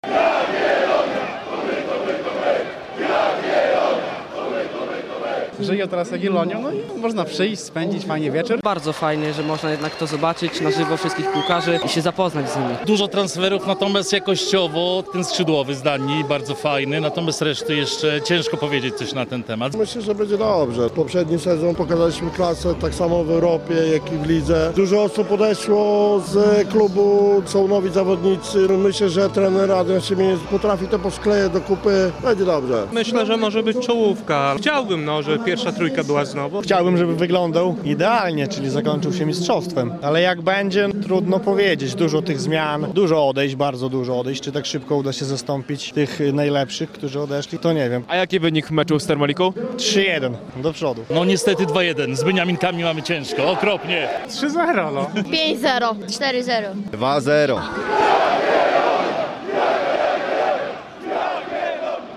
Jagiellonia Białystok na Rynku Kościuszki -prezentacja drużyny przed nowym sezonem - relacja
Na Rynek Kościuszki przyszły tłumy kibiców.